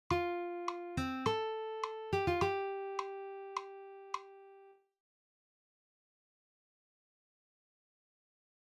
PLAY] (F-C-A-G-F-G). What I like about it is that it sounds like it’s shifting focus from D minor to F major, and that’s a nice contrast to the first phrase ending in minor.